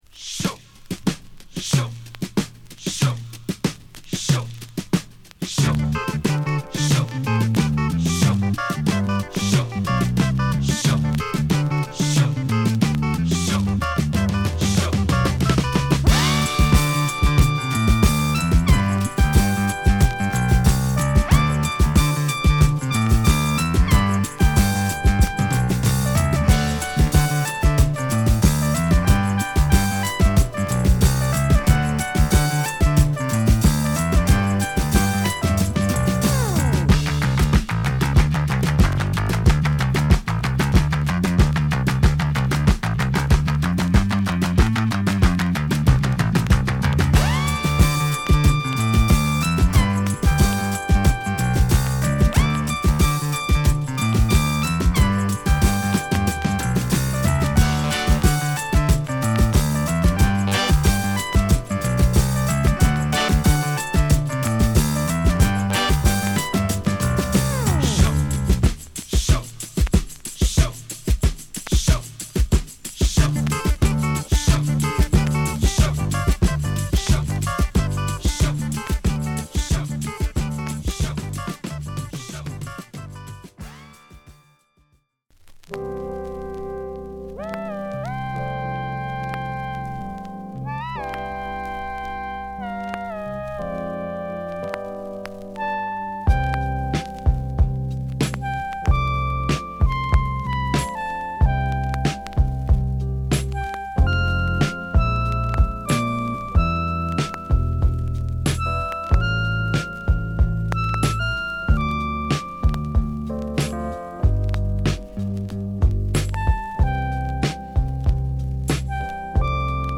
ドラムにギター＆ベース、キーボードと言うムダのない編成で、シンセが舞うディスコ・ファンク・チューンを披露！
＊スリキズ多し。